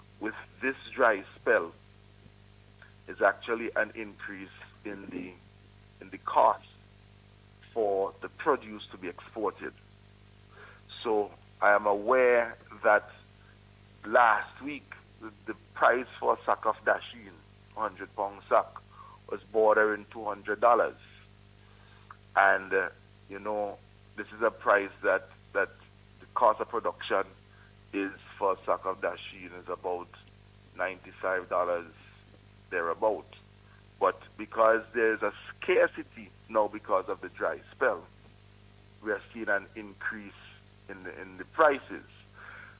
Speaking on NBC’s Face to Face morning show yesterday, Minister Caesar said that the extreme dry conditions have resulted in a scarcity of produce, leading to higher prices.